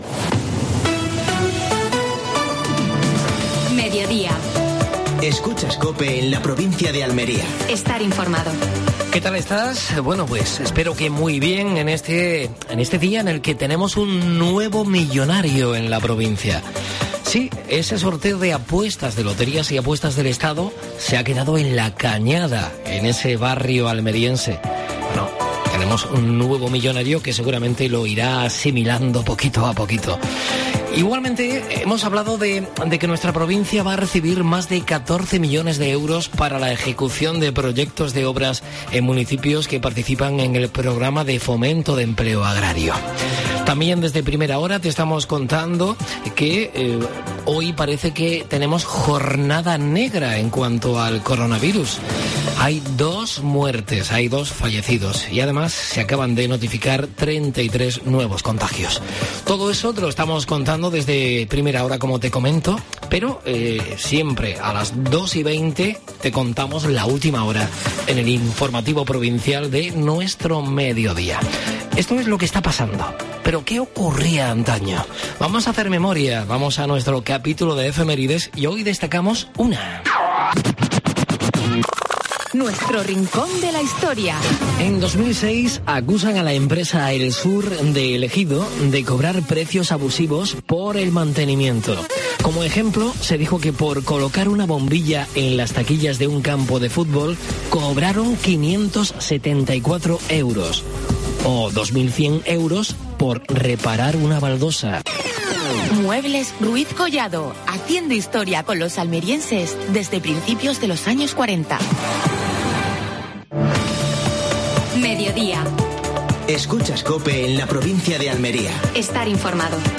AUDIO: Actualidad en Almería. Entrevista a José Antonio García (diputado provincial) en Salón del Gourmet. Última hora deportiva.